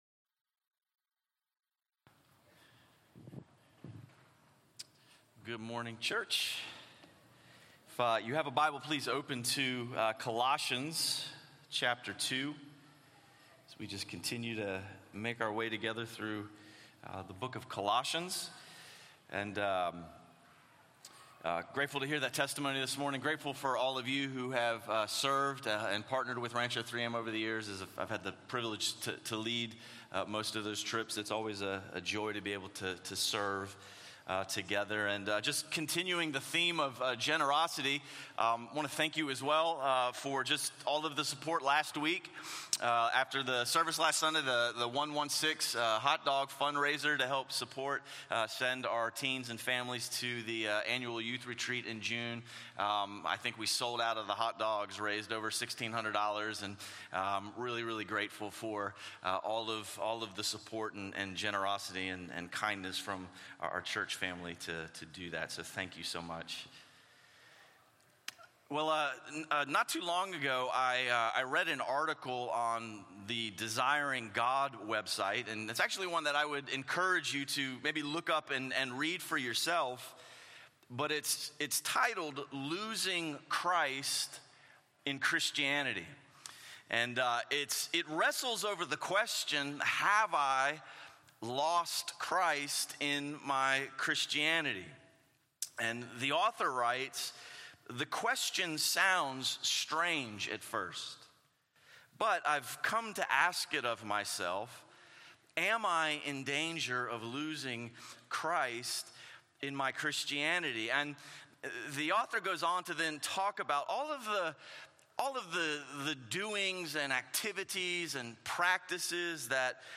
A message from the series "Called Out."